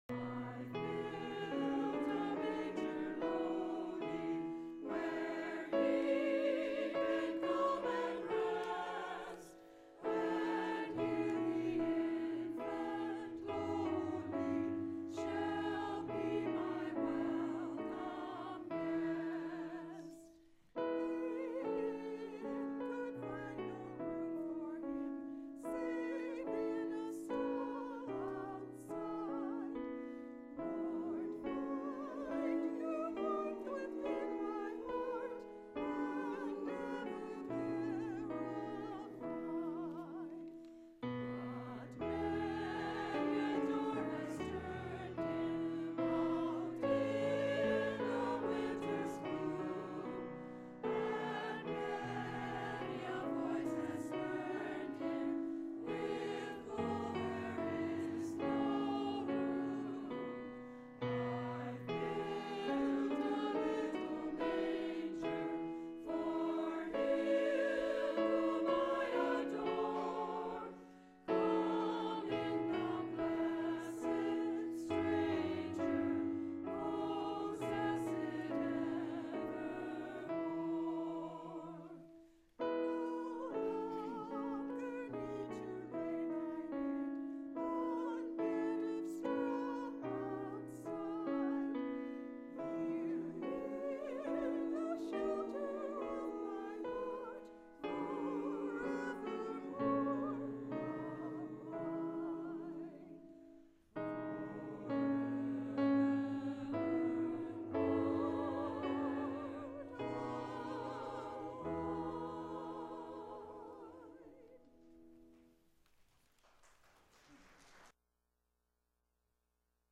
Posted in Sermons on 29.